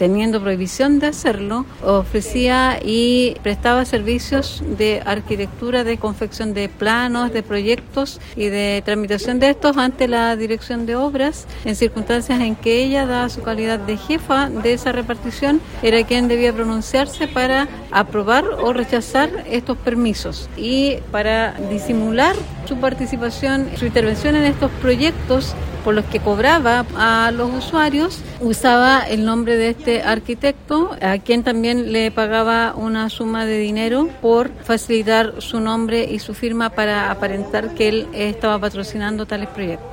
Del modus operandi se refirió la fiscal adjunta de Lota, Glenda Lagos.